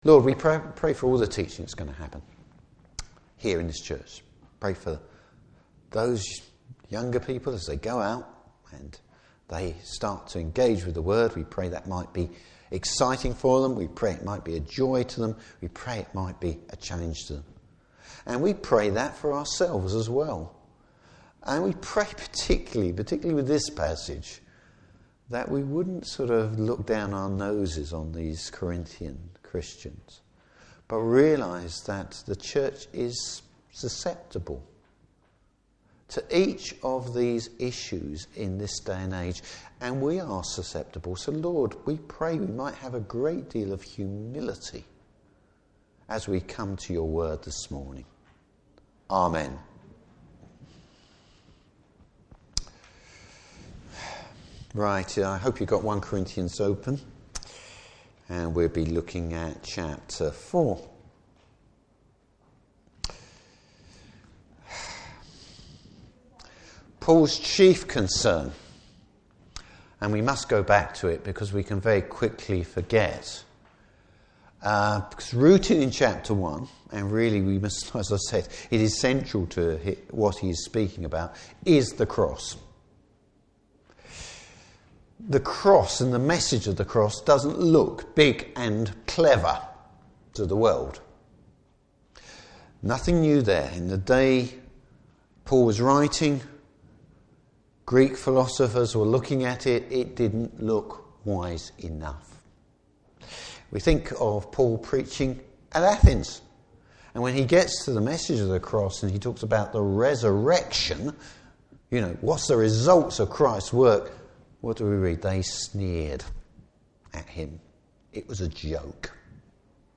Service Type: Morning Service How Paul deals with a proud Church.